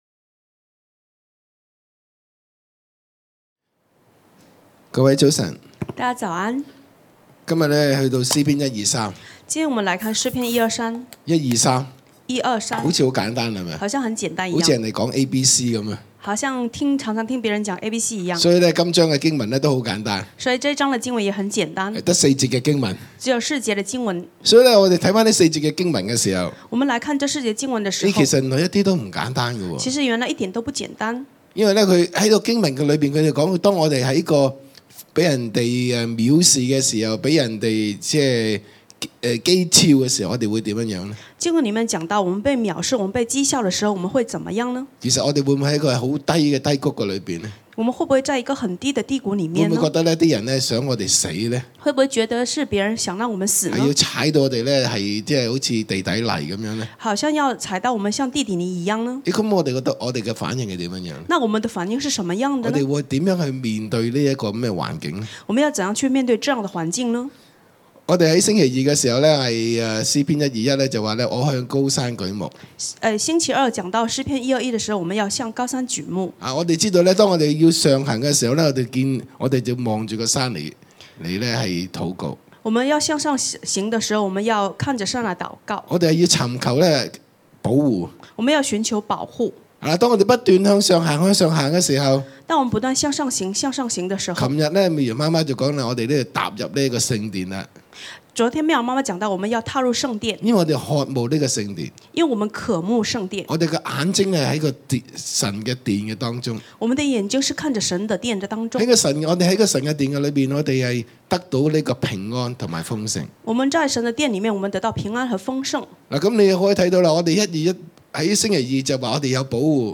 b. 方言禱告，向神呼求祂的恩典和憐憫，除去人及仇敵對我們的藐視、譏誚！